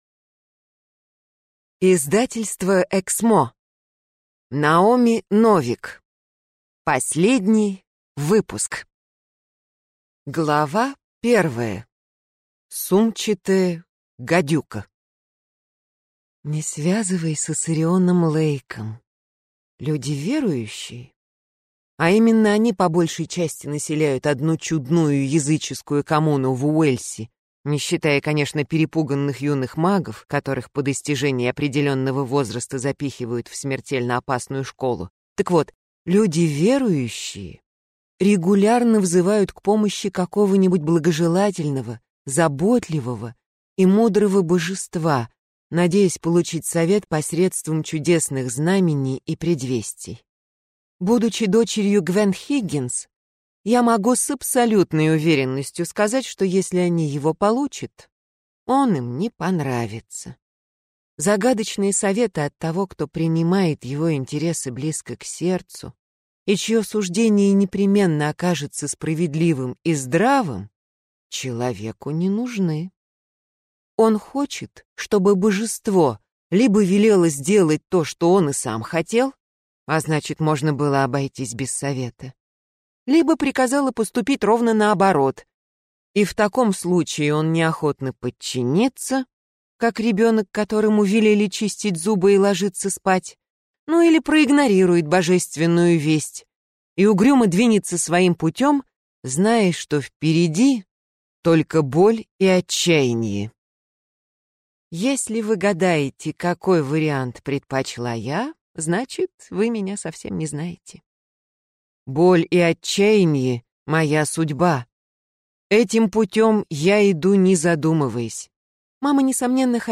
Аудиокнига Последний выпуск | Библиотека аудиокниг